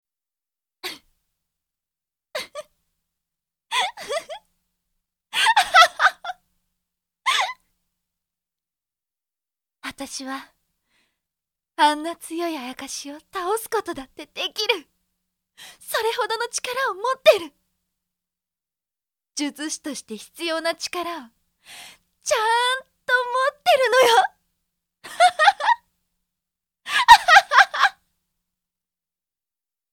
【サンプルボイス】